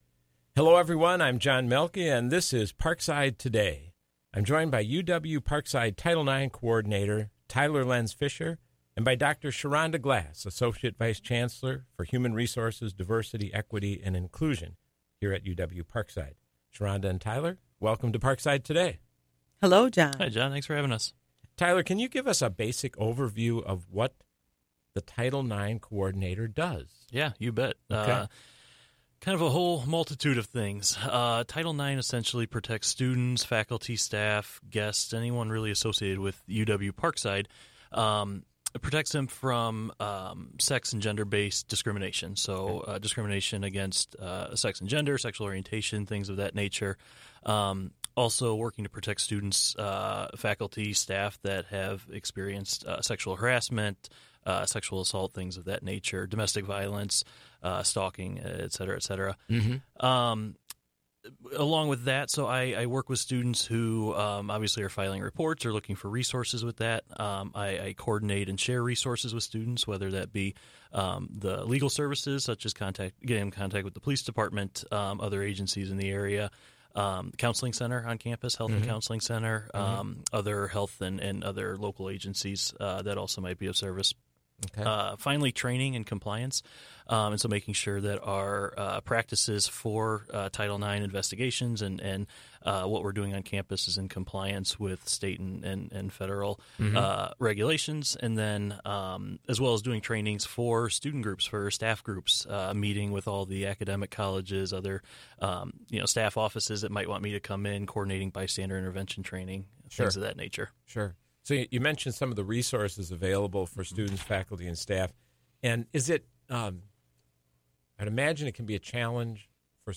This show originally aired on Tuesday, March 3, at 4 p.m. on WIPZ 101.5 FM.